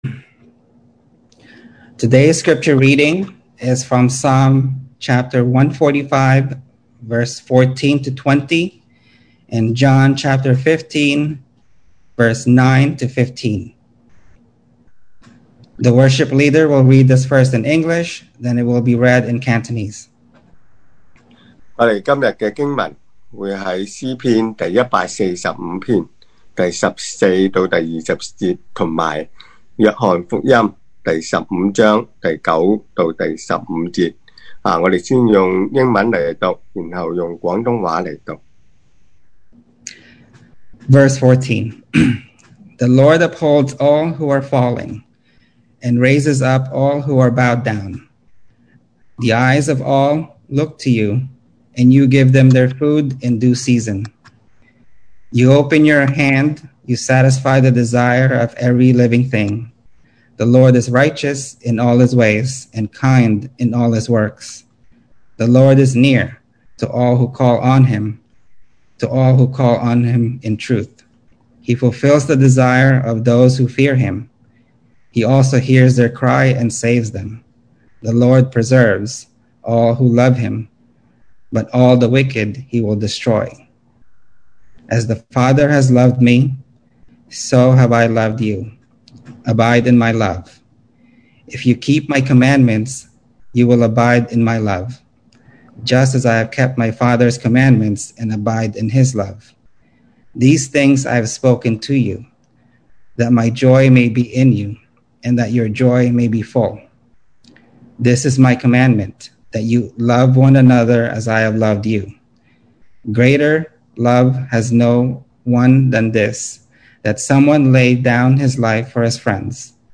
2020 sermon audios
Service Type: Sunday Morning